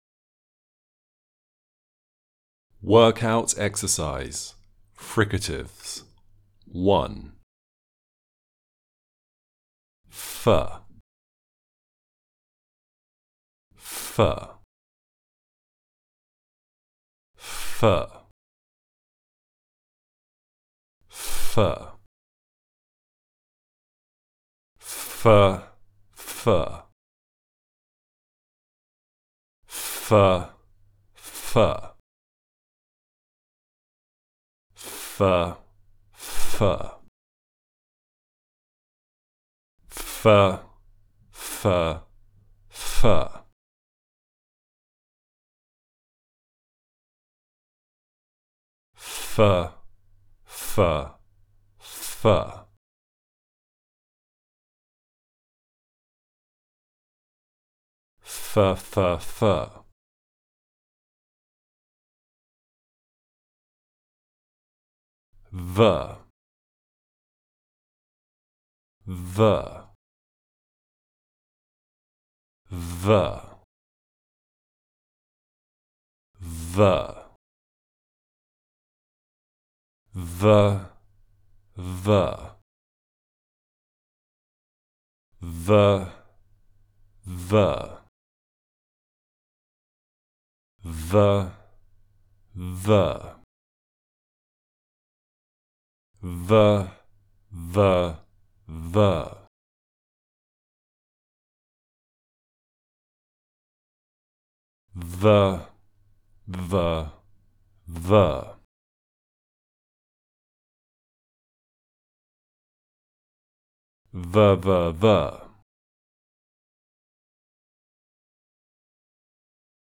The Basic Building Blocks of Speech - Level 01 - British English Pronunciation RP Online Courses
Fricatives 01
05_fricative_01.mp3